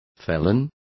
Complete with pronunciation of the translation of felon.